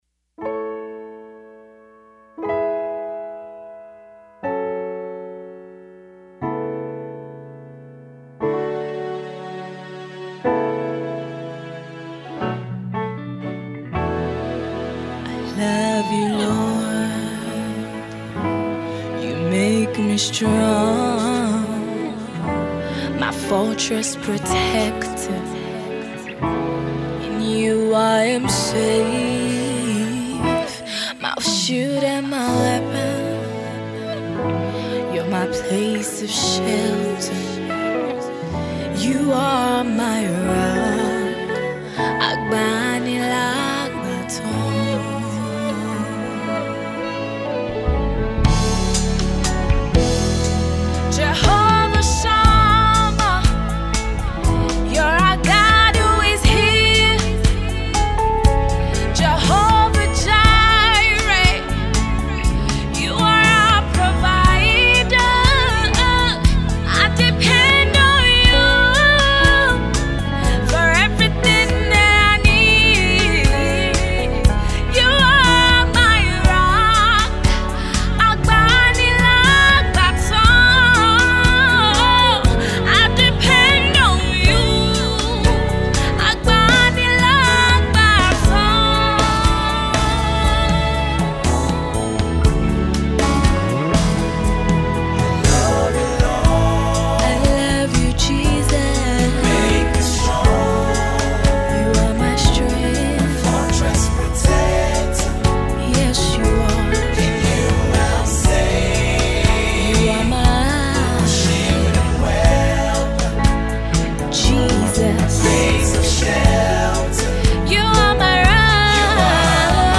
worship song
gospel